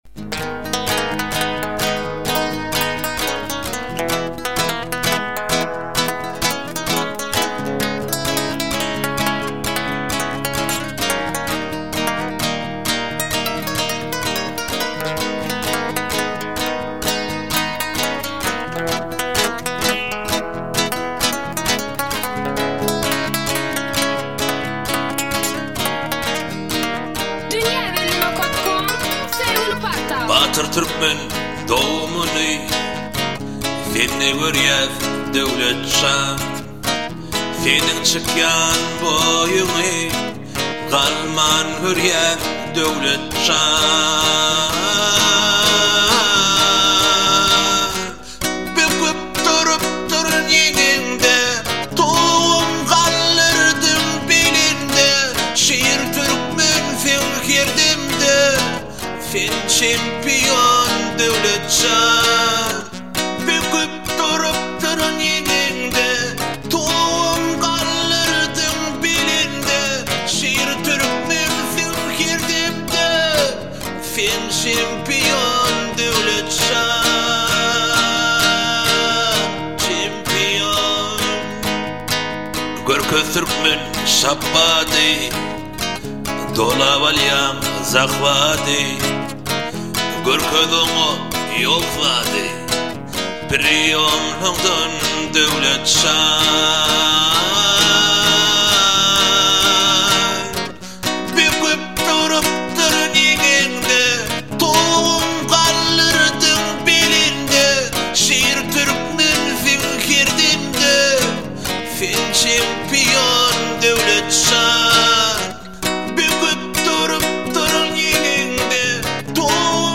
Bölüm: Aydym / Türkmen Aýdymlar